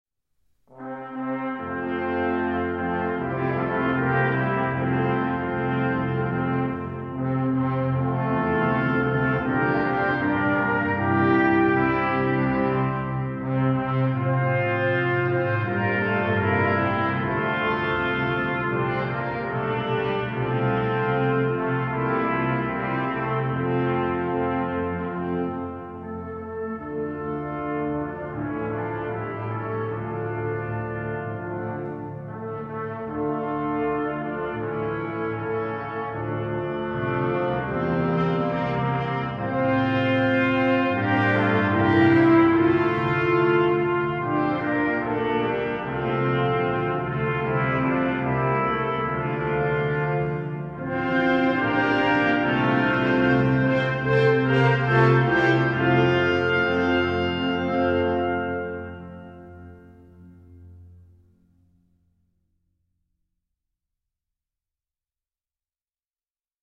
Hymnen
weinritterhymne-hall.mp3